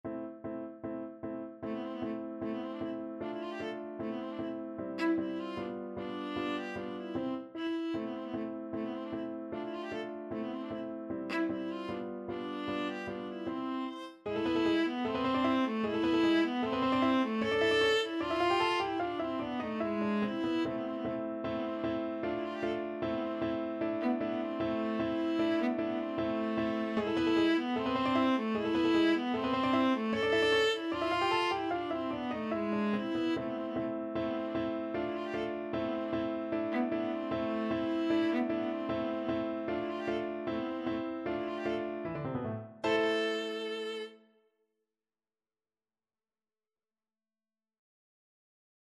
Viola
A minor (Sounding Pitch) (View more A minor Music for Viola )
2/4 (View more 2/4 Music)
Allegro scherzando (=152) (View more music marked Allegro)
Classical (View more Classical Viola Music)